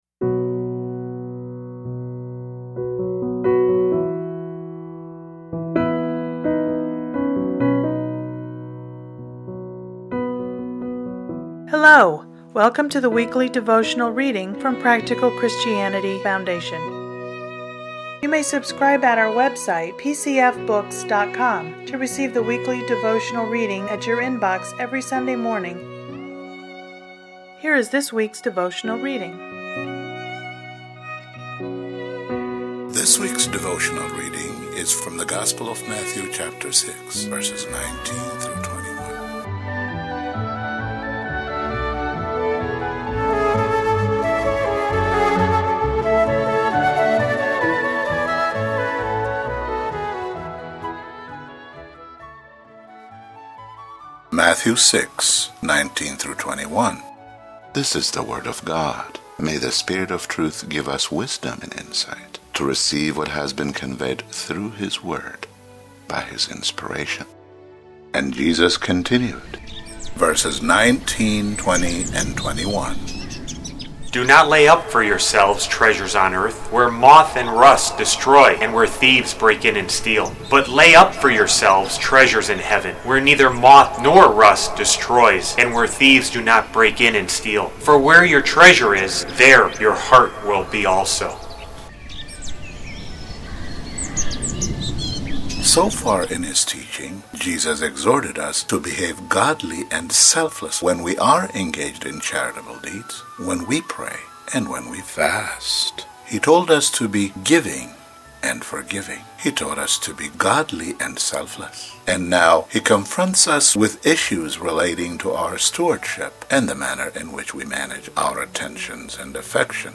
Listen to today's devotional commentary